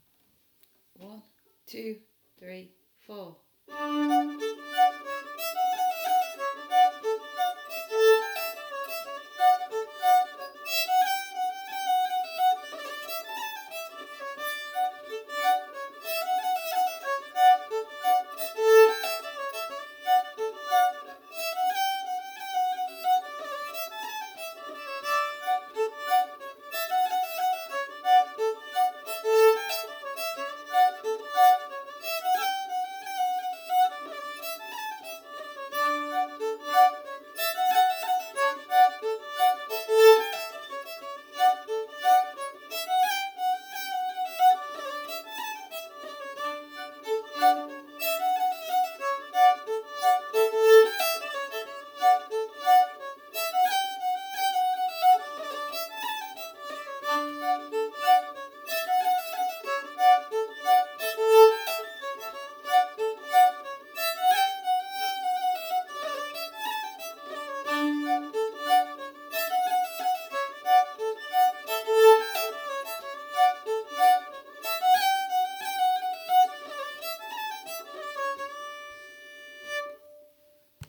B Part Only